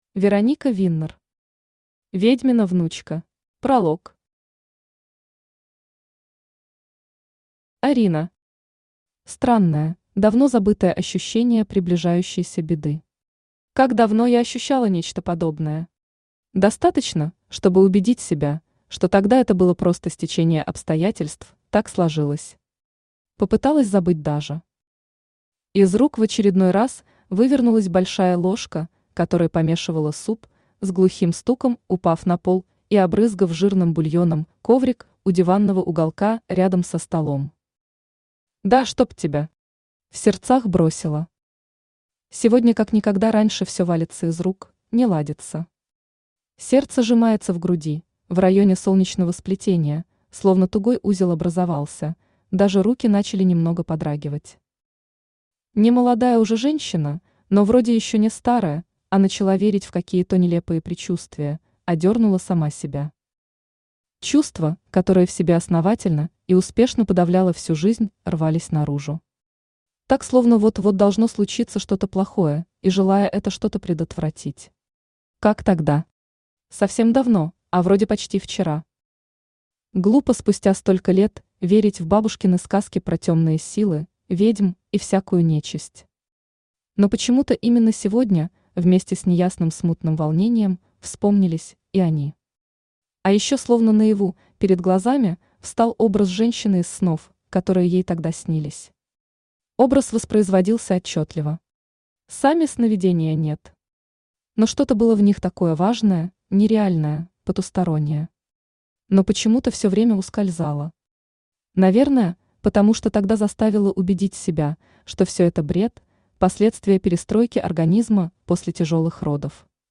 Аудиокнига Ведьмина внучка | Библиотека аудиокниг
Aудиокнига Ведьмина внучка Автор Вероника Виннер Читает аудиокнигу Авточтец ЛитРес.